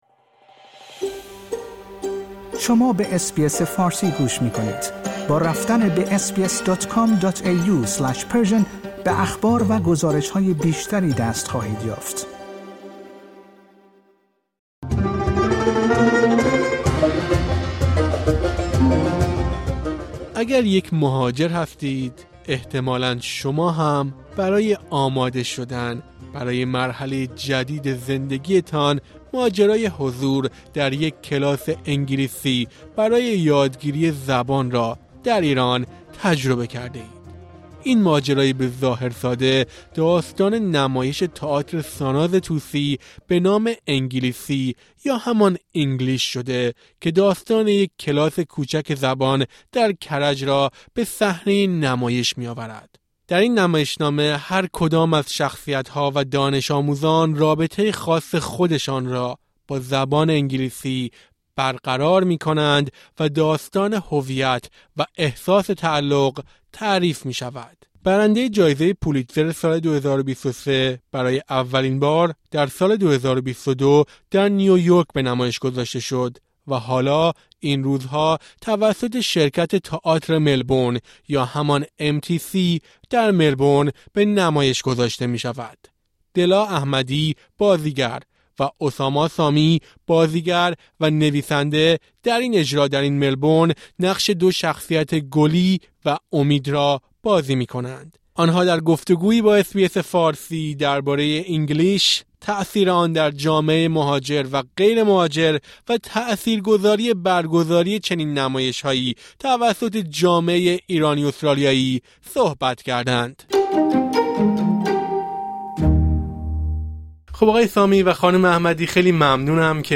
در این گفت‌‌وگو با اس‌بی‌اس فارسی آن‌ها درباره «انگلیسی»، تاثیر آن در جامعه مهاجر و غیرمهاجر و تاثیرگذاری برگزاری چنین نمایش‌هایی توسط جامعه ایرانی‌‌استرالیایی می‌گویند.